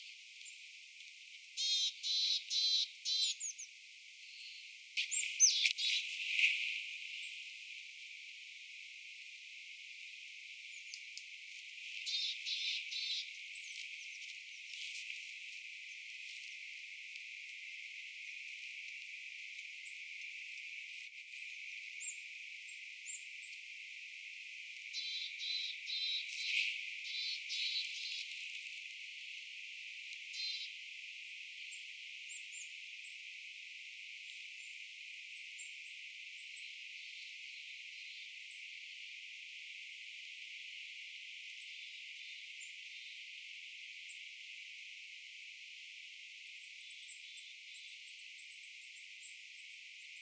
Perinne- ja luontopolulla kävelemässä.
hömötiaisia, hippiäinen
homotiaisia_hippiainen.mp3